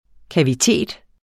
Udtale [ kaviˈteˀd ]